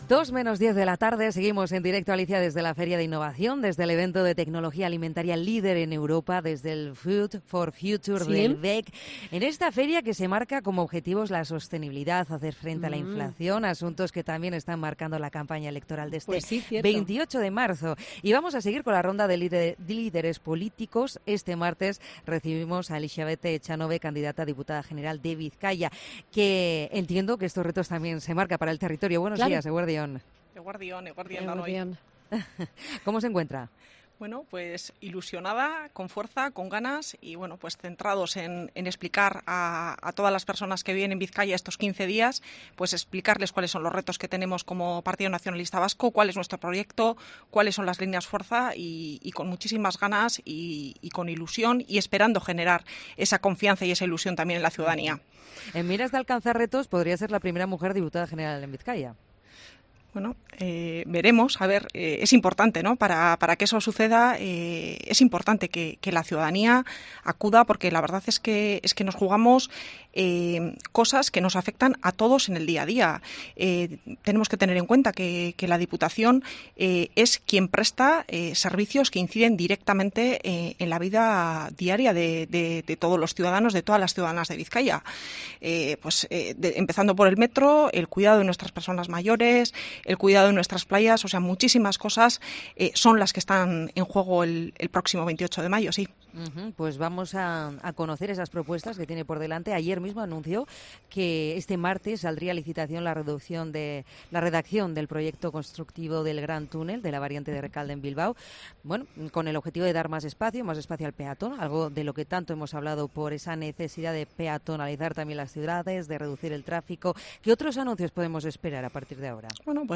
Entrevistada en COPE Euskadi, la candidata jeltzale asegura que su apuesta por el túnel bajo la ría o la ampliación del metro son claves para mejorar la calidad de vida